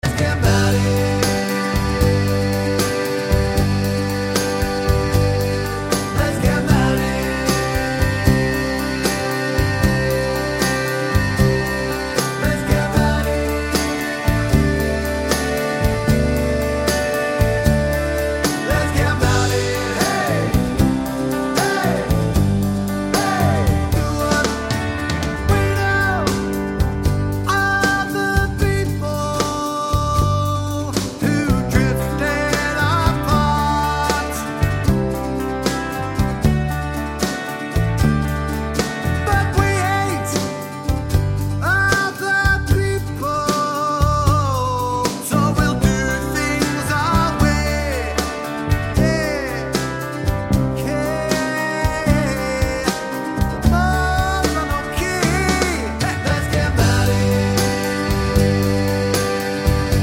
With Harmony Pop (1980s) 4:16 Buy £1.50